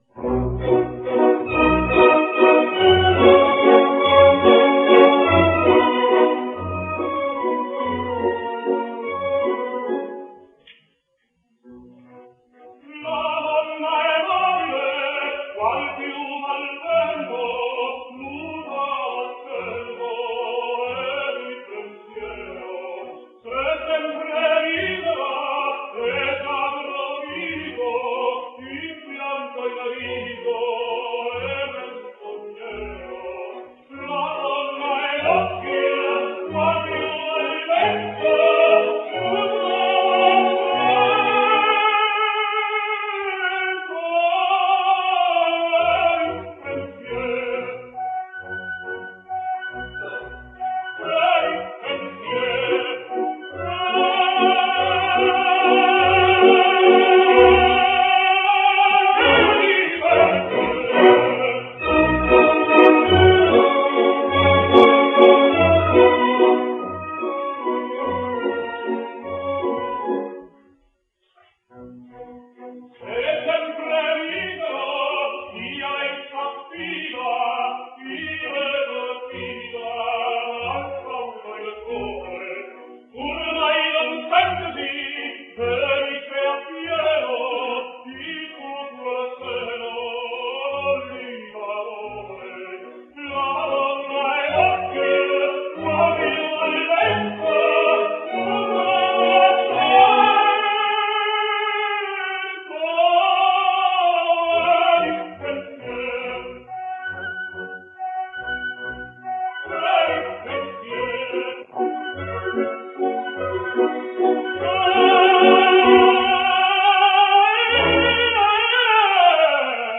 José Carreras sings Rigoletto:
carreraladonnaemobiledemonstratinghistechnique.rm